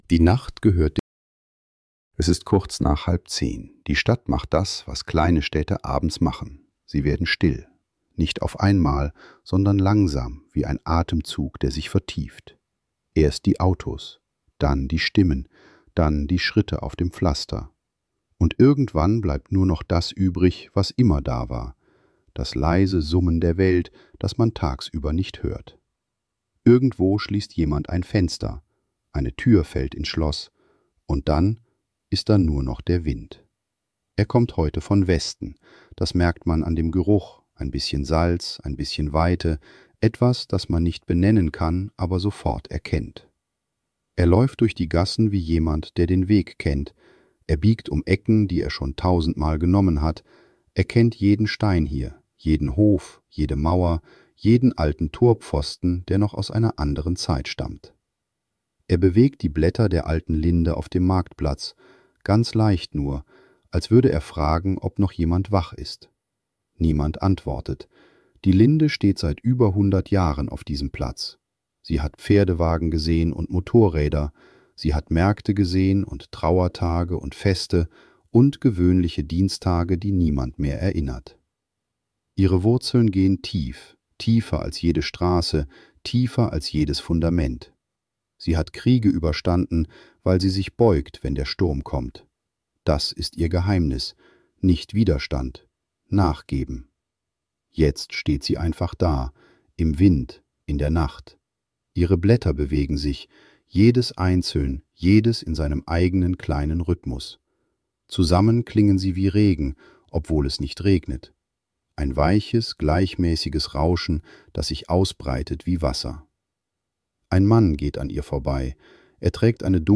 In dieser Episode wandert der Wind durch eine schlafende Stadt und begleitet Menschen, die in der Nacht wach sind. Leise Routinen, echte Orte und das ruhige Vor-und-Zurück der Nacht verweben sich zu einer sanften, atmosphärischen Einschlafgeschichte.